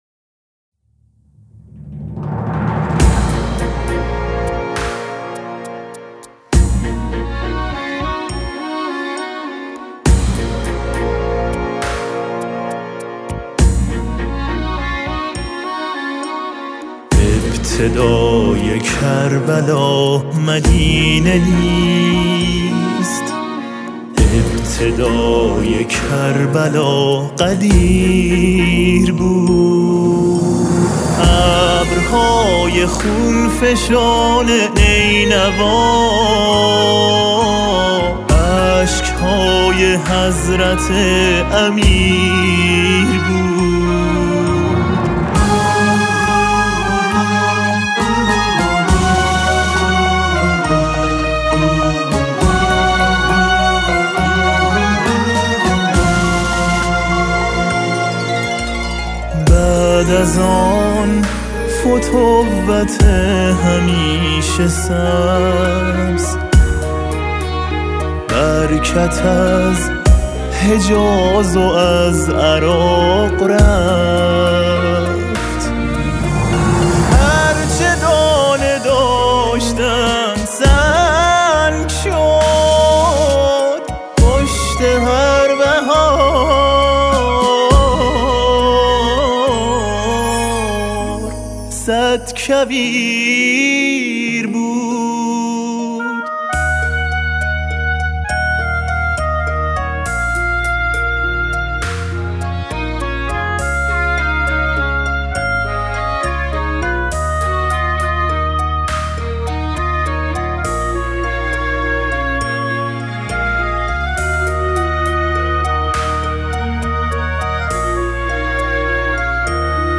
ویلون و ویلون آلتو
سه تار
پیانو و سازهای الکترونیک